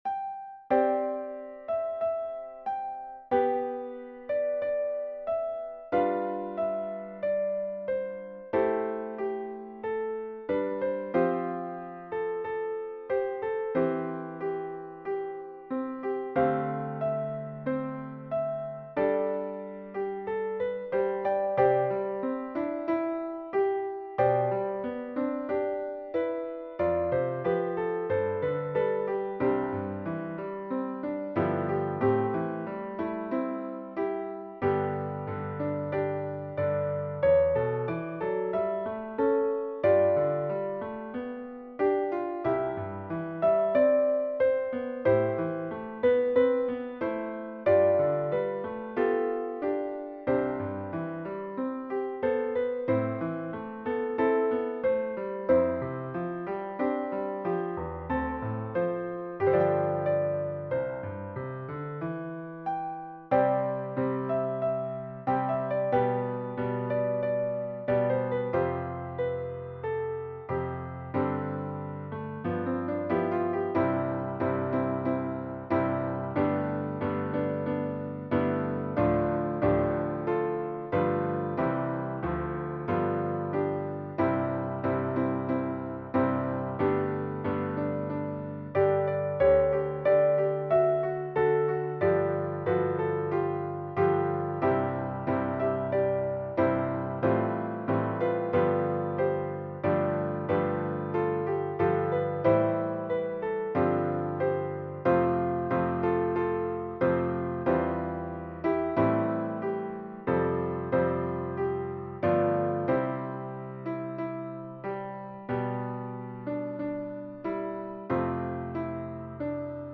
America the Beautiful--Hymn #338 SATB with piano accompaniment, written as a hymplicity-style arrangement.
There is a soloist for the first verse that is optional (can have the choir sing in unison).
Voicing/Instrumentation: SATB We also have other 16 arrangements of " America the Beautiful ".
Choir with Soloist or Optional Soloist